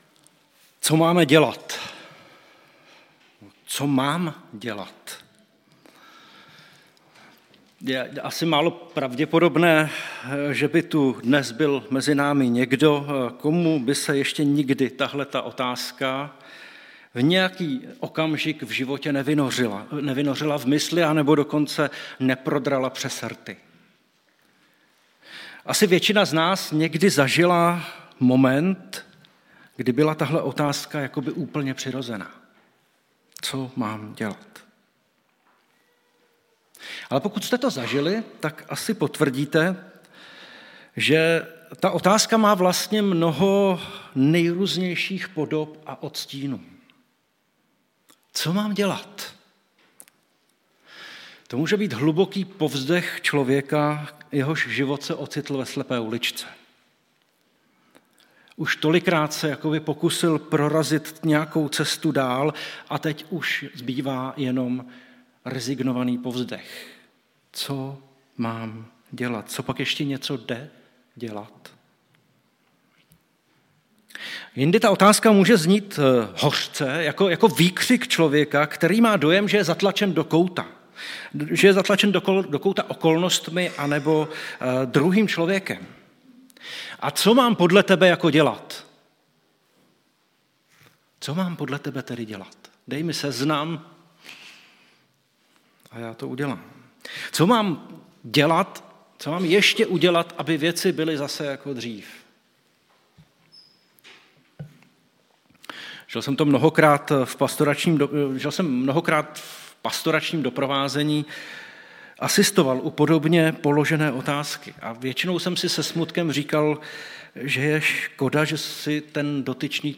Křestní bohoslužba - Co máme dělat?